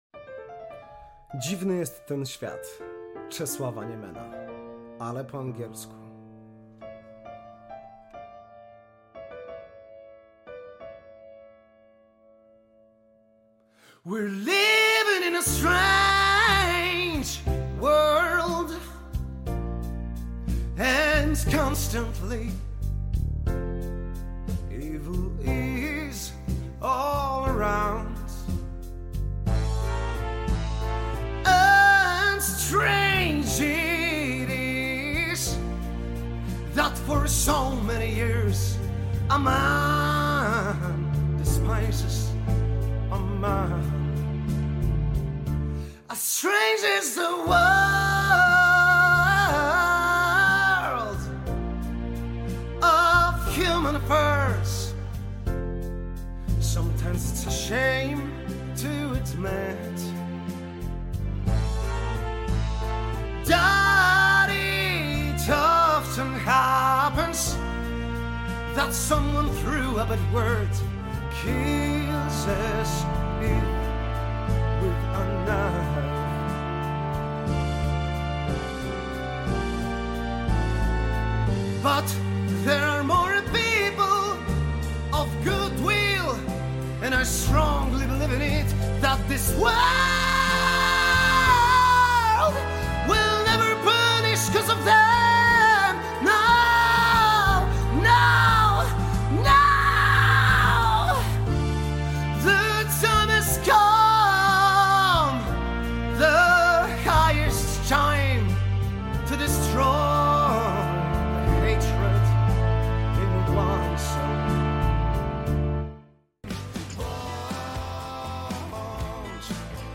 to kolejny polski hit śpiewany po angielsku